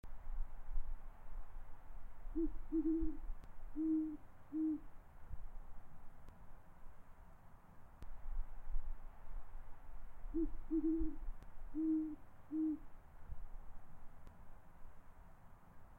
Audio Call